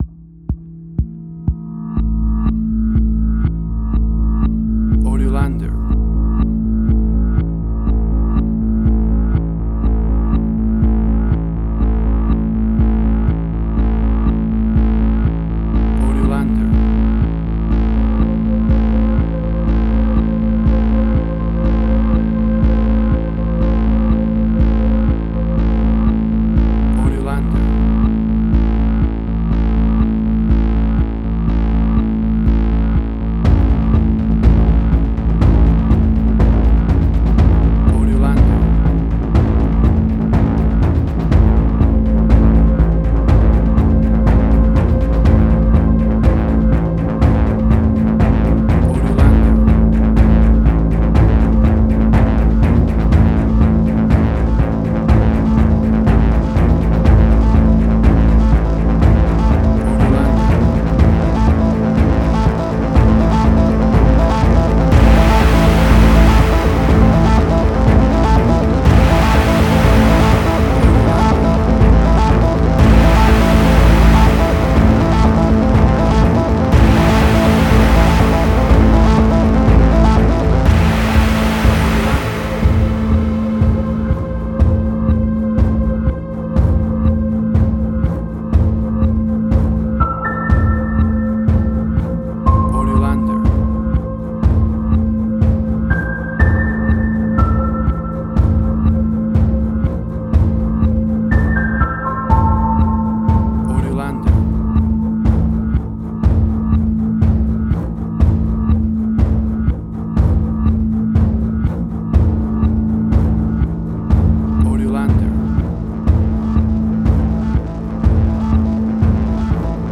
Modern Science Fiction Film, Similar Tron, Legacy Oblivion.
Tempo (BPM): 122